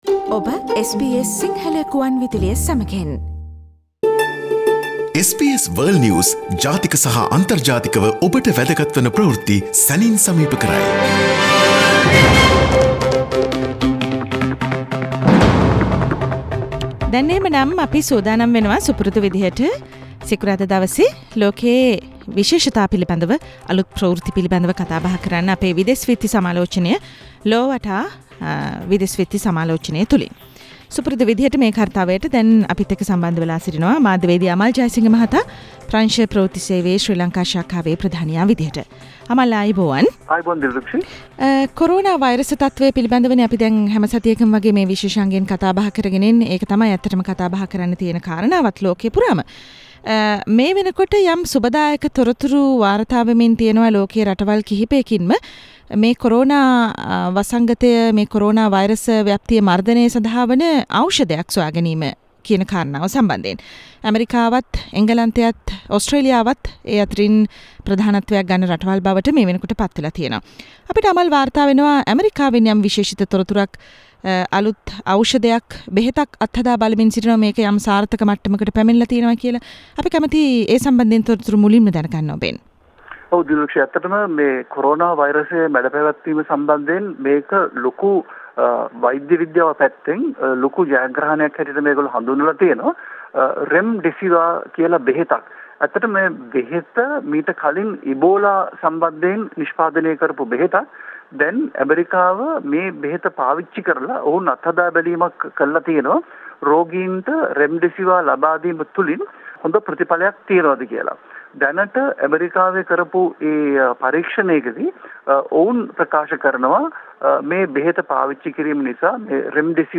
SBS Sinhalese weekly world news wrap Source: SBS Sinhala radio